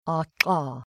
CONSONANTI (NON POLMONARI)